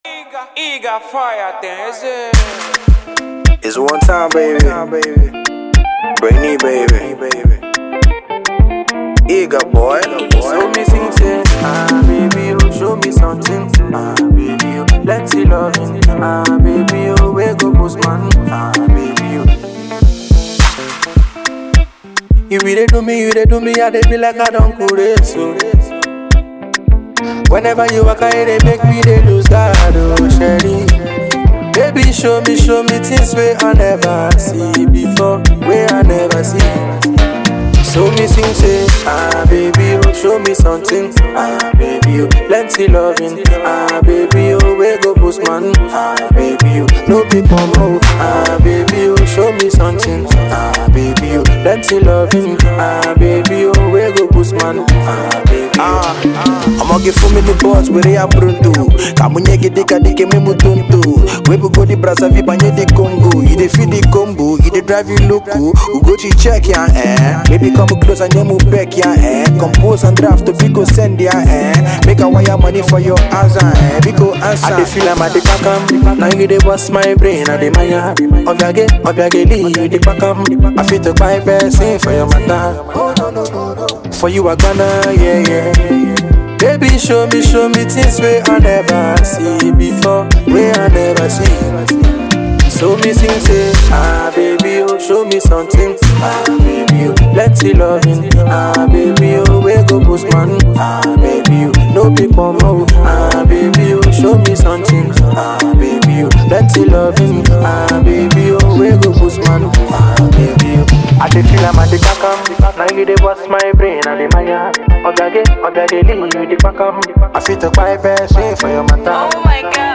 Nigerian Afro hip-hop
guitarist